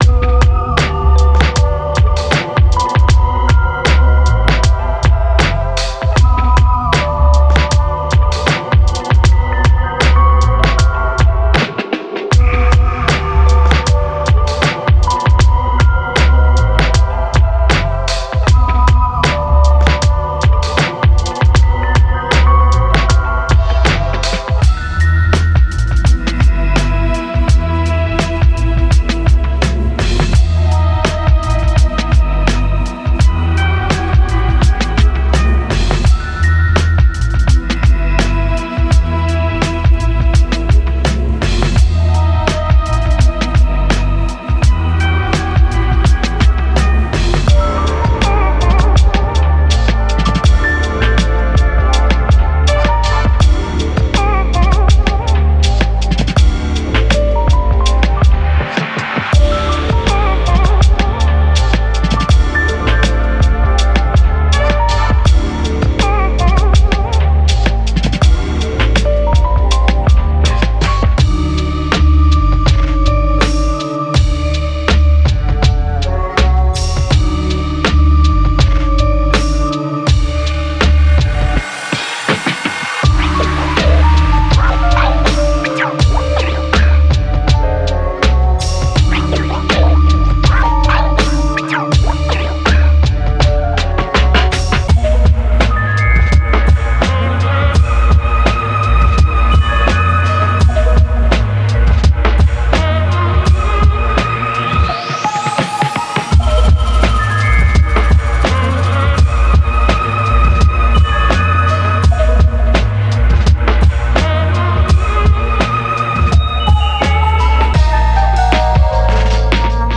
Genre:Hip Hop
MPC特有のスウィング感を持つパンチの効いたドラムブレイク
ジャズ、ソウル、オブスキュアなトーンからスクラッチで作られたムーディーなメロディループ
ヴィンテージ感のあるローエンドのパンチを備えたダーティなベースライン
クラックル音やテープヒスによる真のレコード掘り感